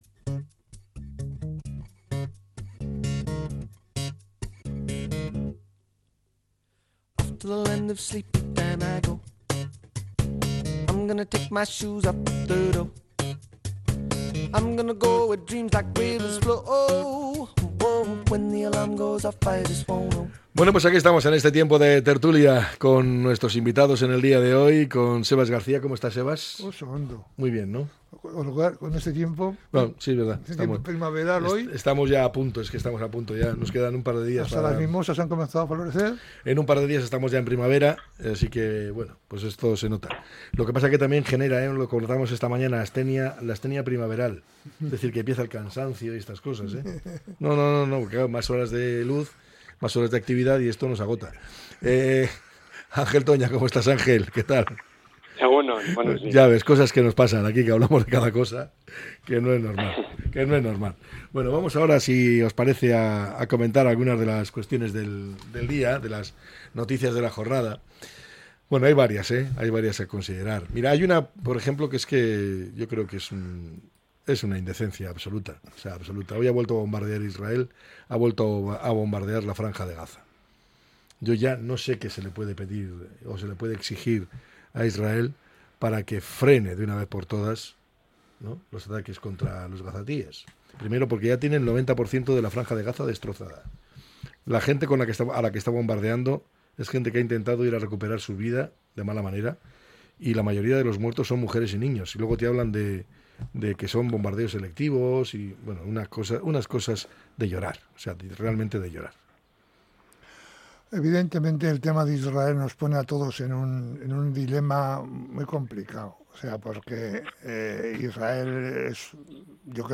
La tertulia 19-03-25.